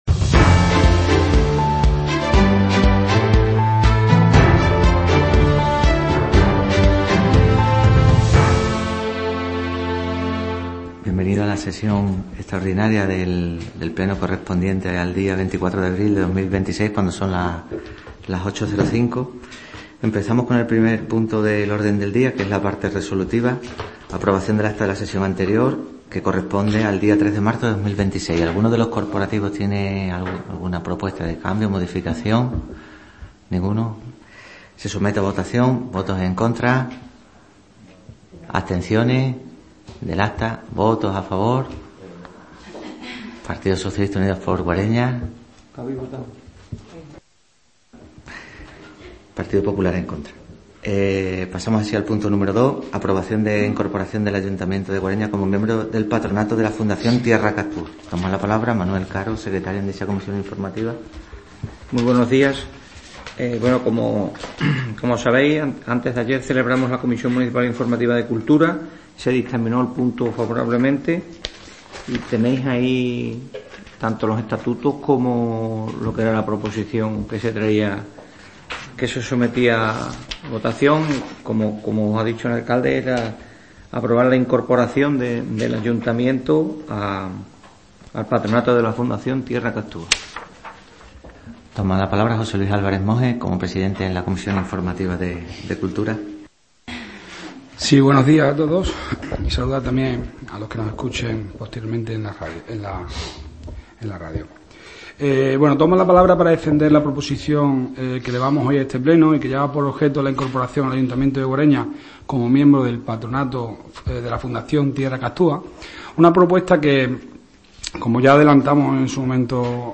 Pleno Extraordinario 24.04.2026 (Audio íntegro) – Ayuntamiento de Guareña
Sesión celebrada en el Ayuntamiento de Guareña.